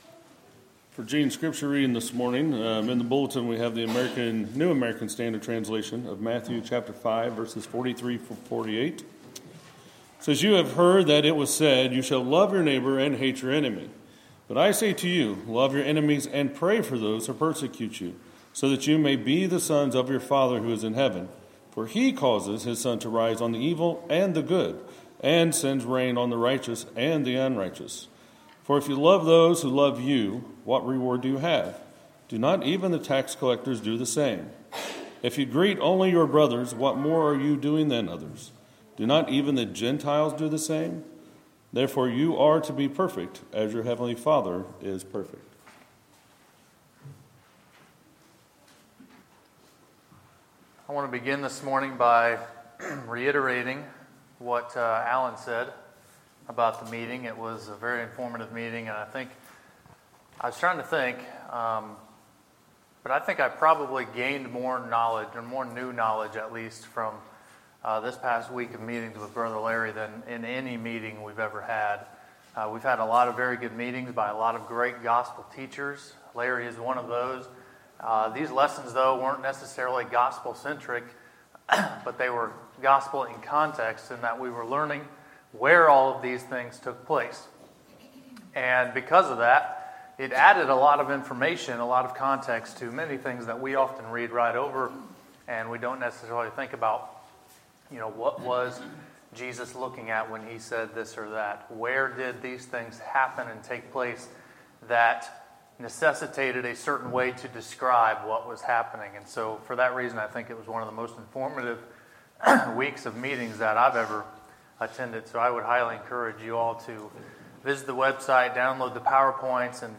Sermons, September 17, 2017